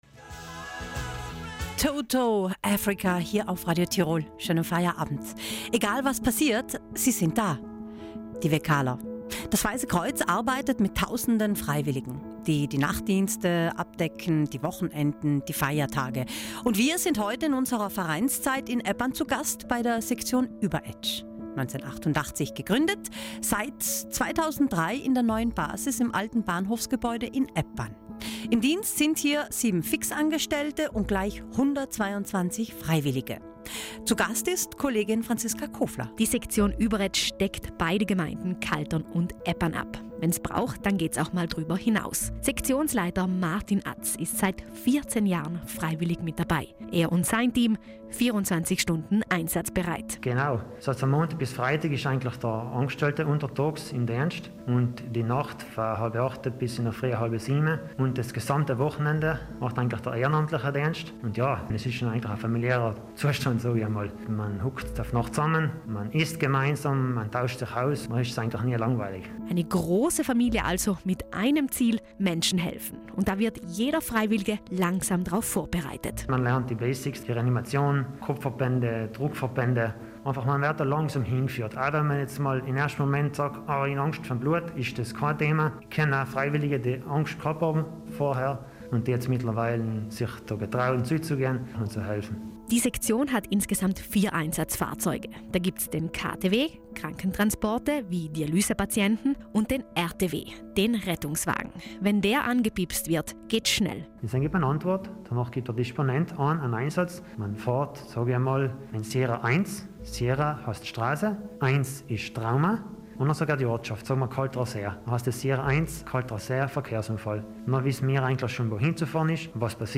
In der Radio Tirol Vereinszeit sind wir dieses Mal beim Weißen Kreuz, Sektion Überetsch, zu Gast. 1988 gegründet, seit 2003 in der neuen Basis im Bahnhofsgebäude in Eppan. Das Team zählt 7 Angestellte und 122 Freiwillige.